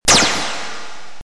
Photon sound
Category: Sound FX   Right: Personal
Tags: Photon Sounds Photon Sound Photon clips Sci-fi Sound effects